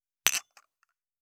274,食器をぶつける,ガラスをあてる,皿が当たる音,皿の音,台所音,皿を重ねる,カチャ,
コップ効果音厨房/台所/レストラン/kitchen食器